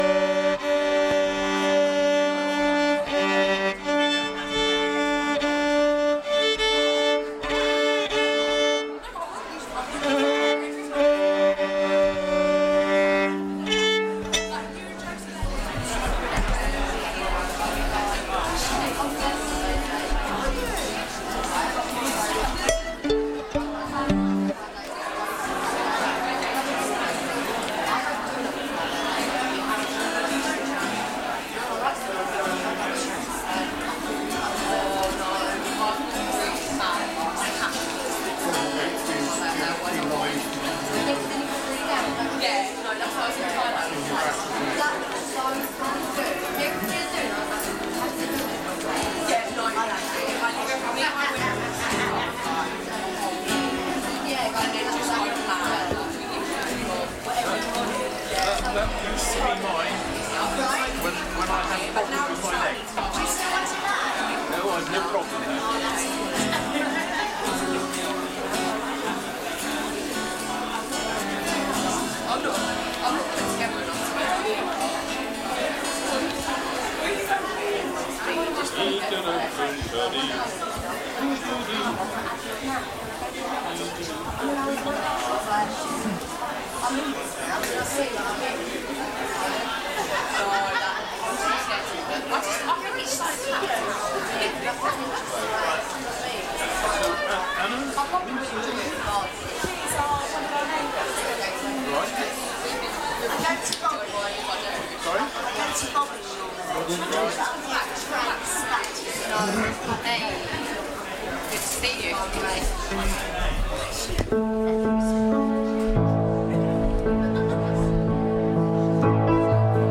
Morning service - Sittingbourne Baptist Church
Join us for this morning's baptism service.